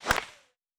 Puck Hit Slapshot.wav